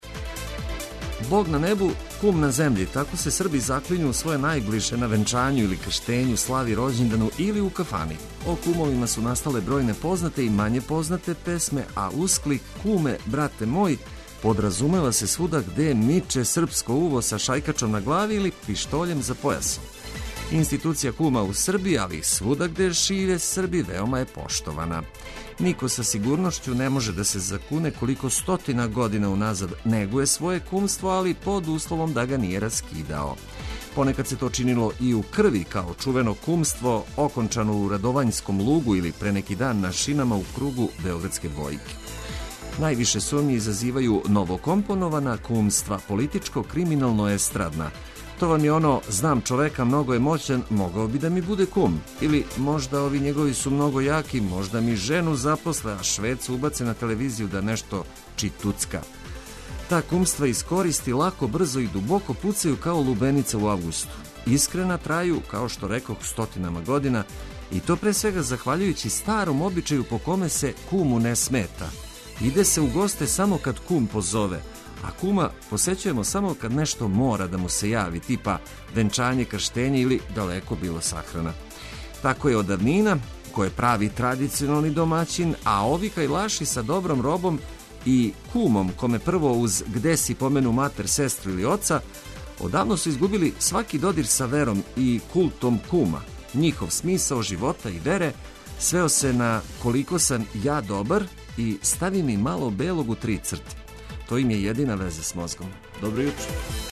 Током јутра најважније информације уз музику која ће разведрити јутро.